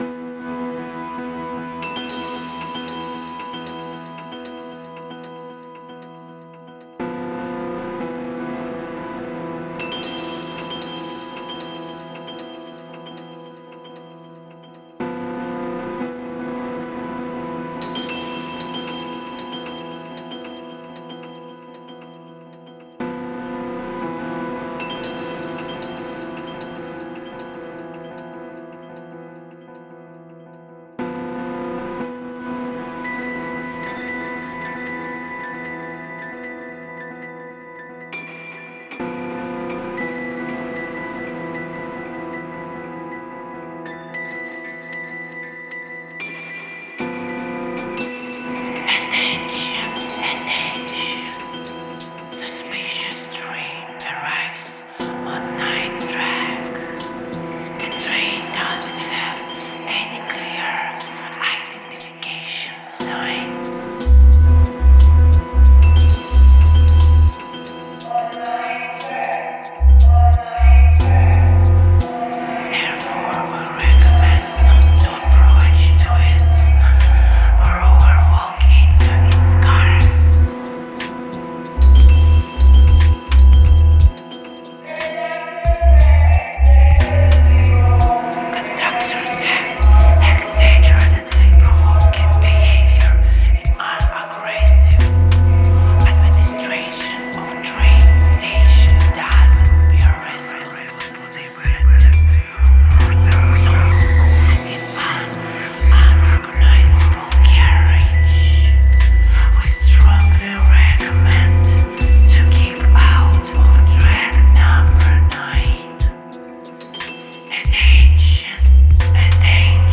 • Жанр: Electronic, Indie